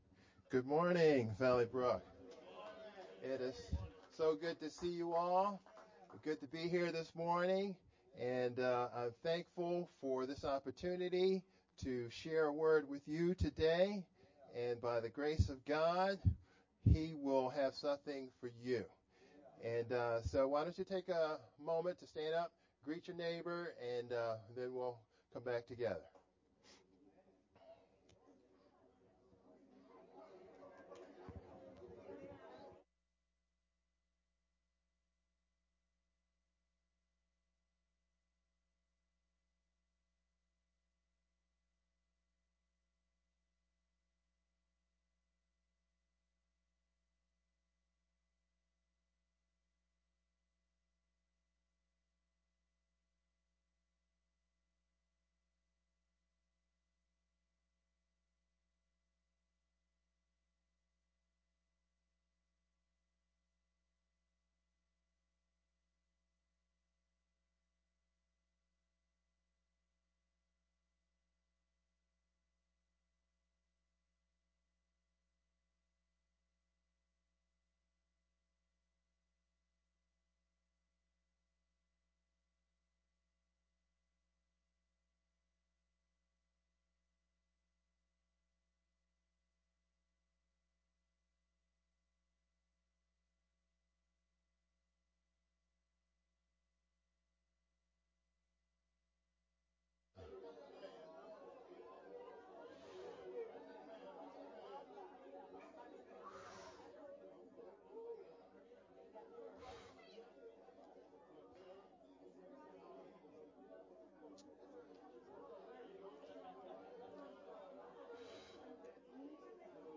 VBCC-Aug-18th-edited-sermon-only_Converted-CD.mp3